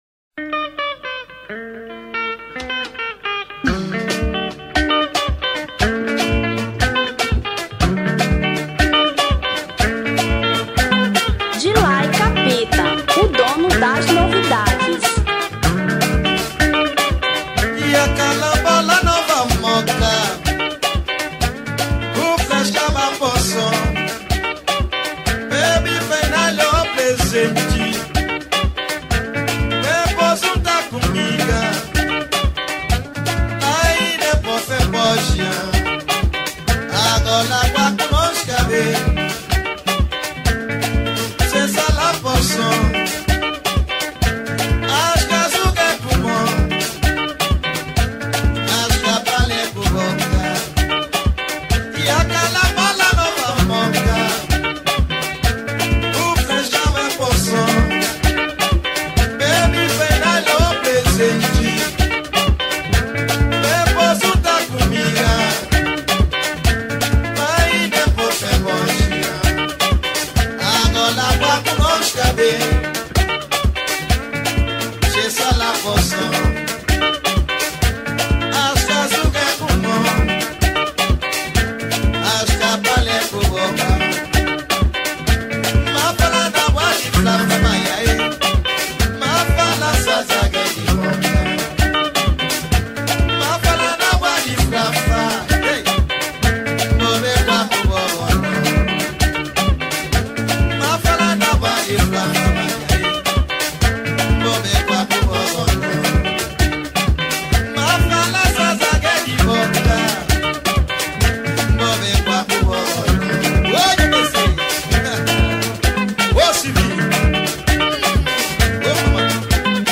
Sungura